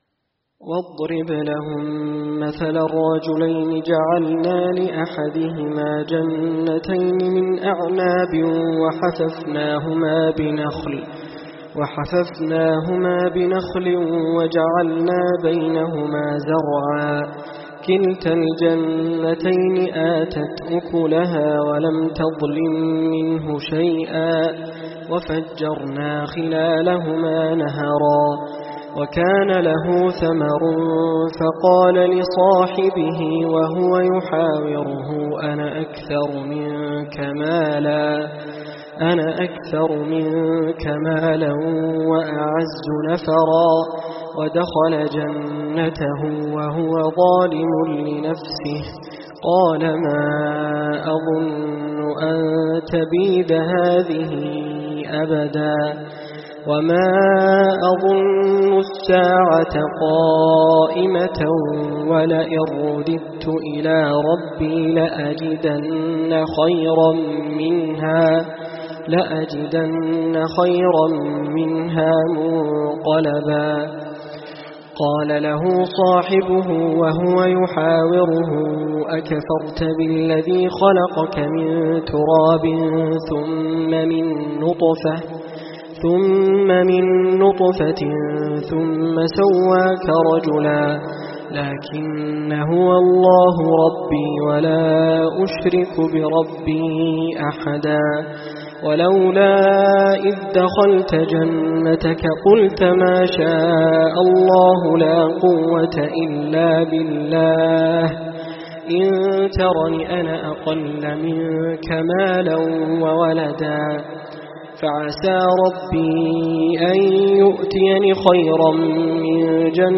سورة الكهف من الآية ٣٢ إلى آخر السورة - تراويح ١٤٤٦هـ من جامع أبو عمرين شعبي بصامطة
الصنف: تلاوات
رواية : حفص عن عاصم